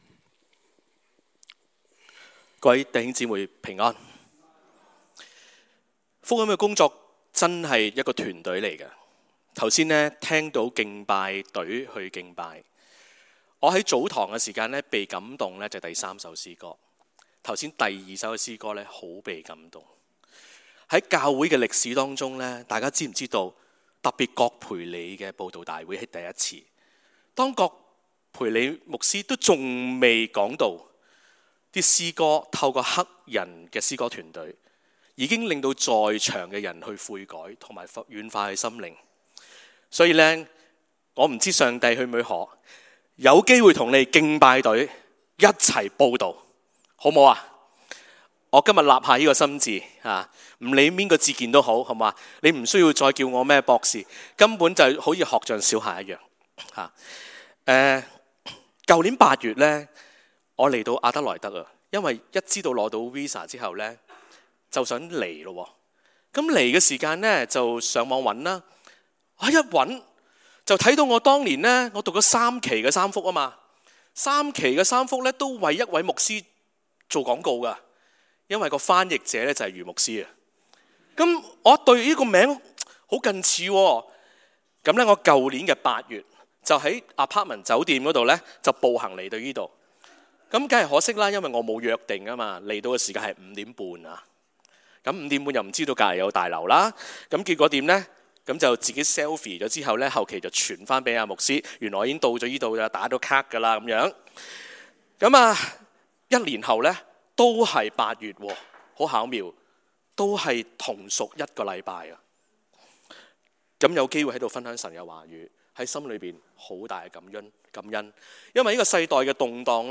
在： Sermon